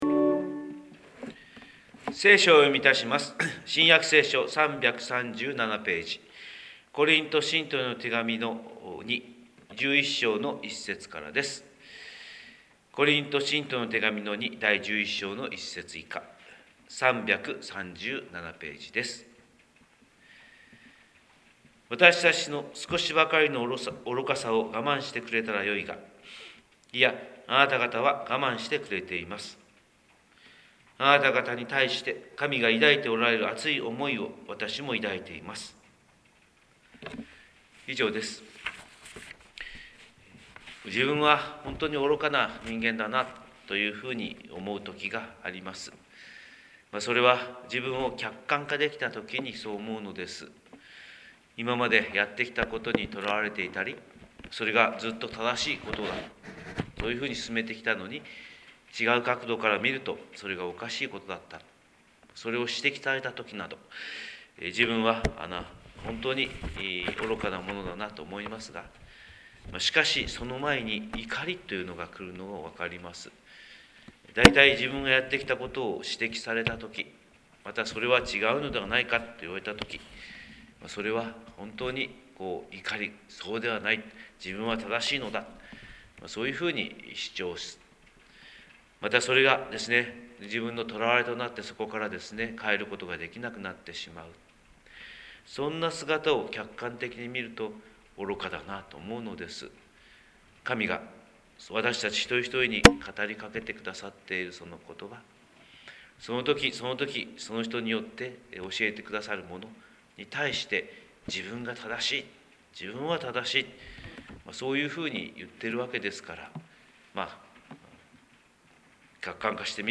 神様の色鉛筆（音声説教）
広島教会朝礼拝250319